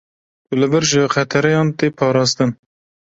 /pɑːɾɑːsˈtɪn/